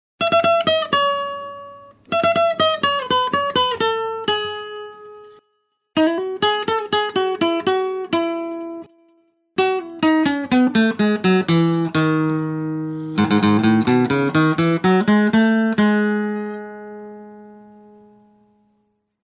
古典吉他领唱1
标签： 125 bpm Acoustic Loops Guitar Acoustic Loops 1.62 MB wav Key : Unknown
声道单声道